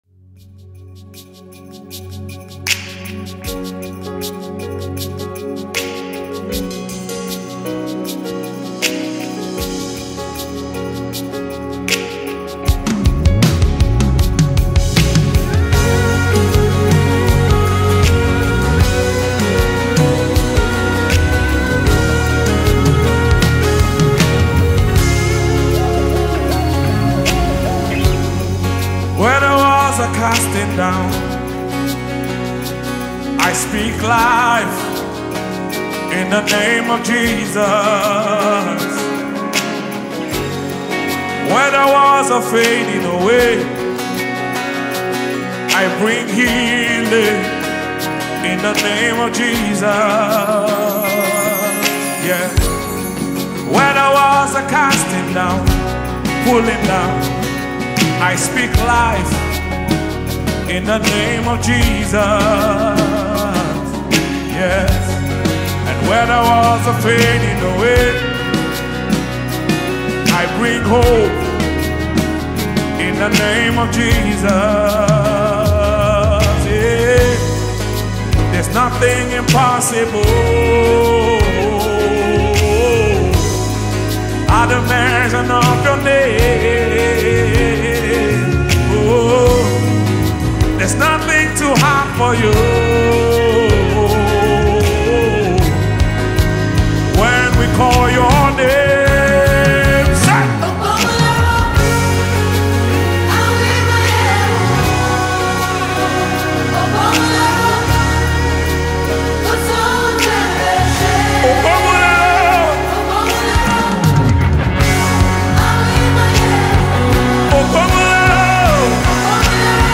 a soulful melody that lifts your spirit
create a smooth melody that is both calming and uplifting
Genre: Gospel